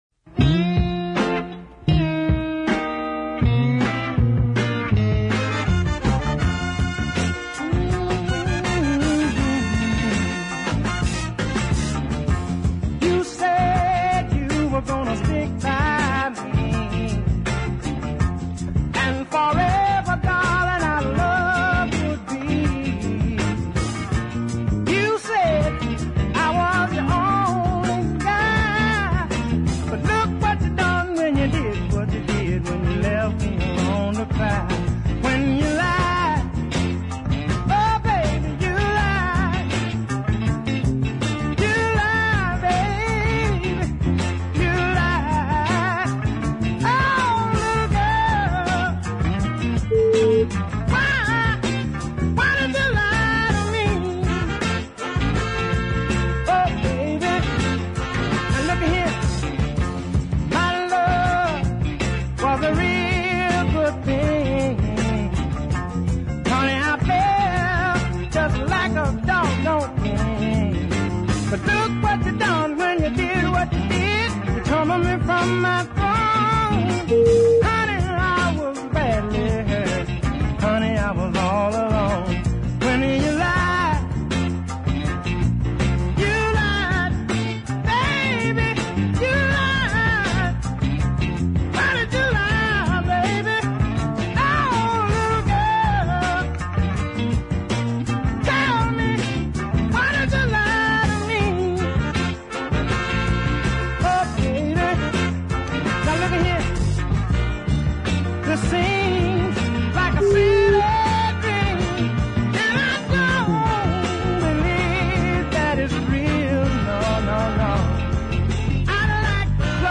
no nonsense soul
throaty vocals well to the fore